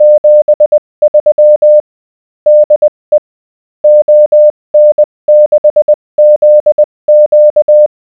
The GNU Octave program below generates a sound file (.wav) with the morse code representing a given text.
Smoother...
MorseSoundFileGenerator_2.wav